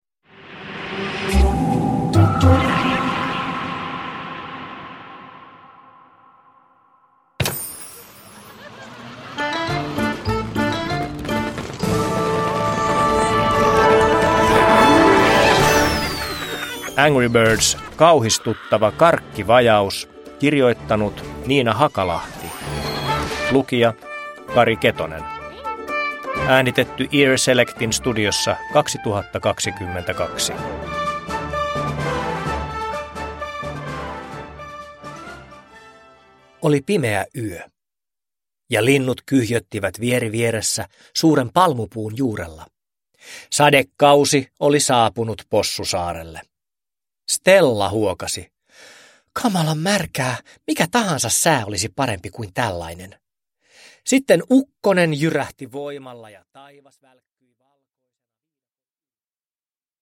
Angry Birds: Kauhistuttava karkkivajaus – Ljudbok – Laddas ner